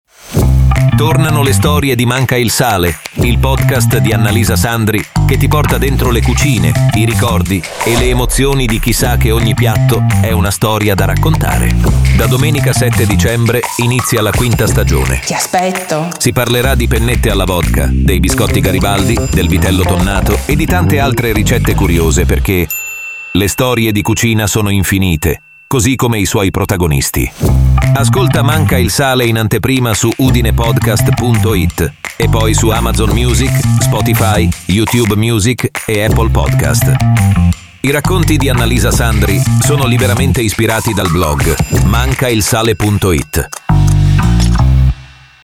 MANCAilSALE-PROMO_5-stagione.mp3